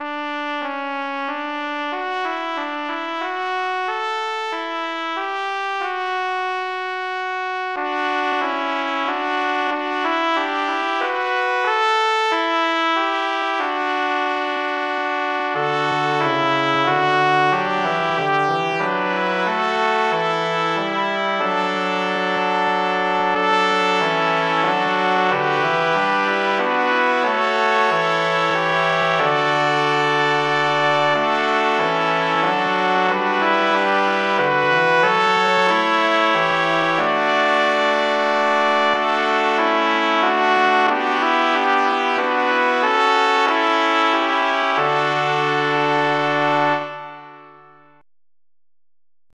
Kanon